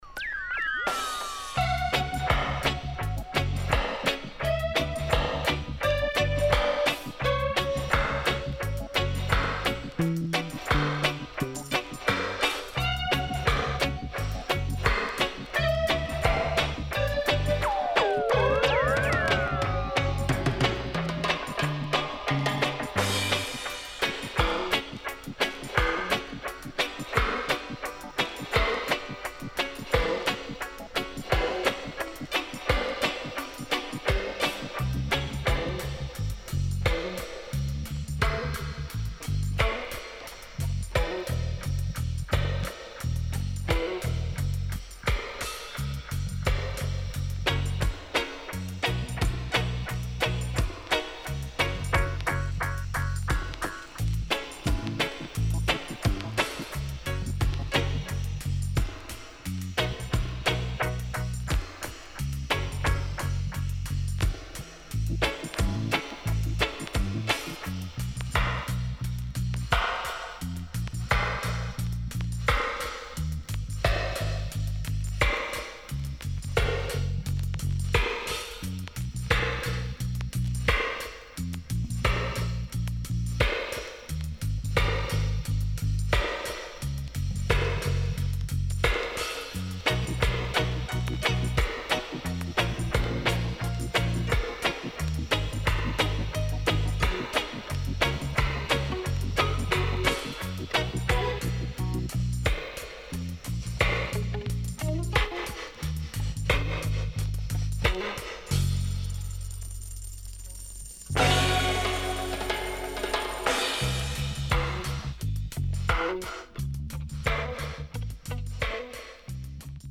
UK Great Lovers Classic & Dubwise
SIDE A:うすいこまかい傷ありますがノイズあまり目立ちません。